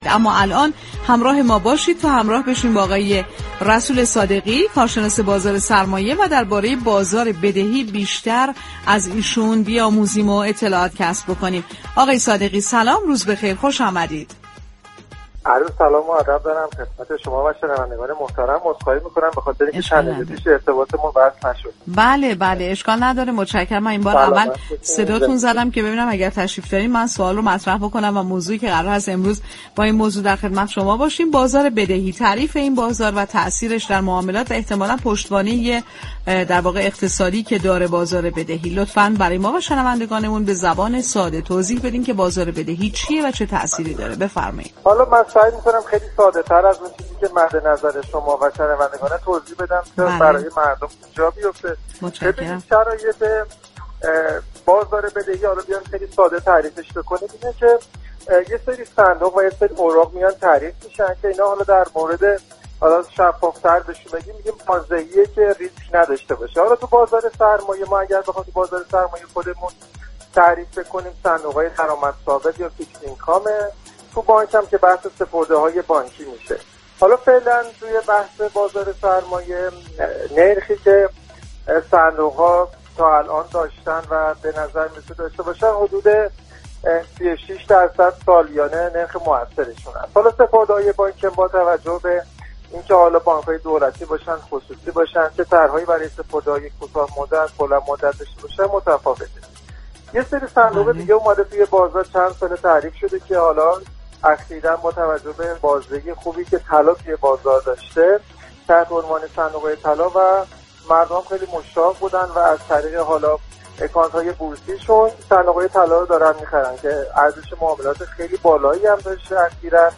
یك كارشناس بازار سرمایه در گفت‌و‌گو با رادیو تهران با اشاره به نقش نرخ بهره، صندوق‌های درآمد ثابت و صندوق‌های طلا در این بازار، تأكید كرد كه كنترل نرخ بهره توسط بانك مركزی می‌تواند به خروج صنایع از ركود و رونق تولید كمك كند.